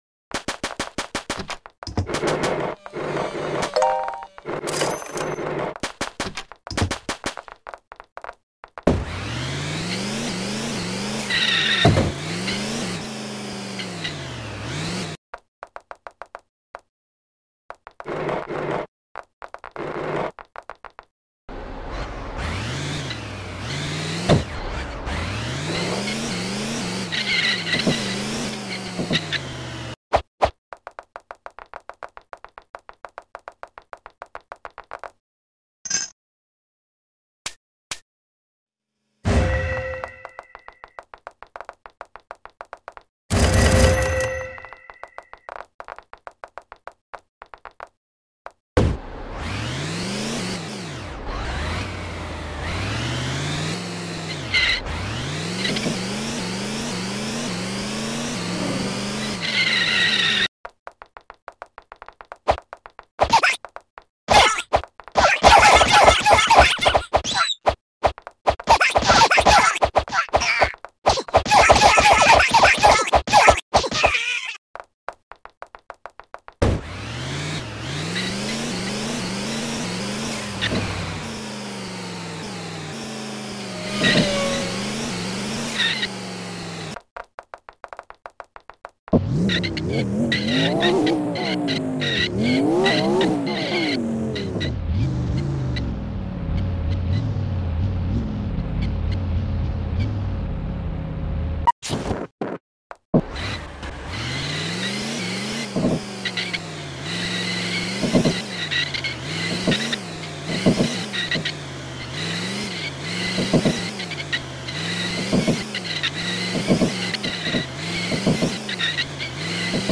All Police Cars Collection 🚓 Sound Effects Free Download